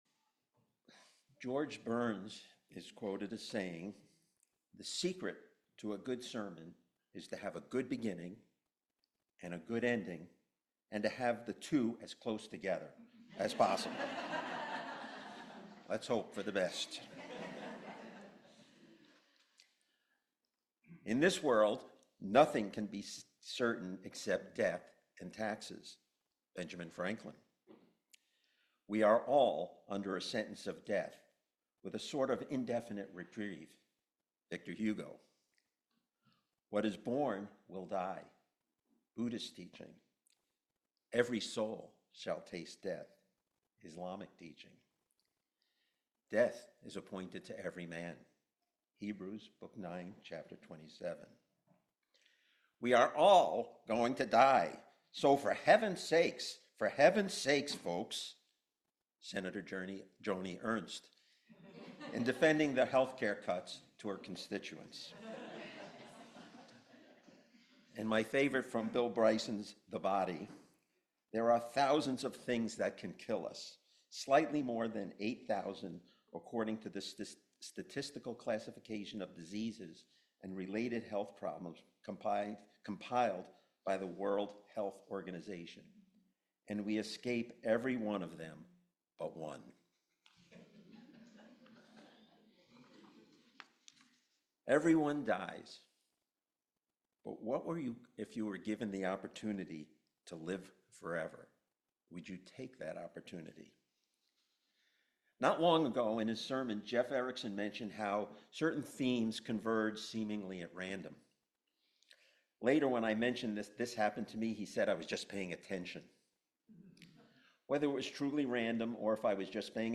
This sermon explores the concept of eternal life by contrasting pop culture, literature, and traditional religious dogma with Unitarian Universalist perspectives.